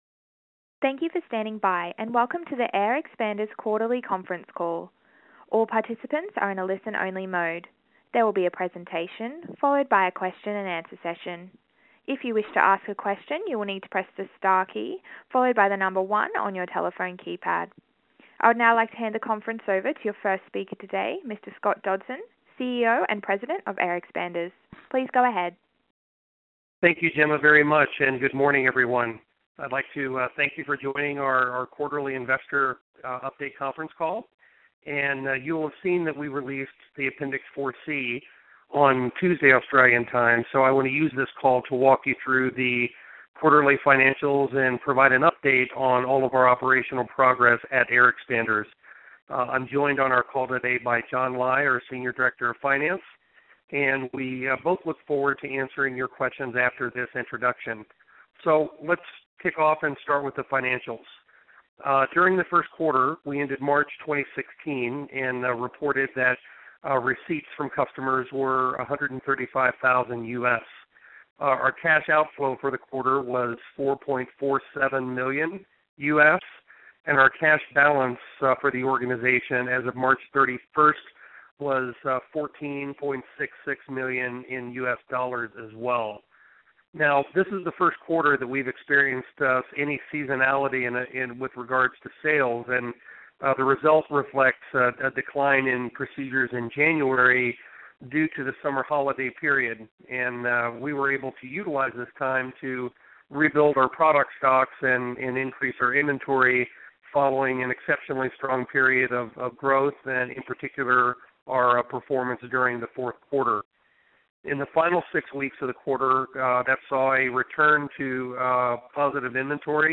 AXP 1Q2016 Conference Call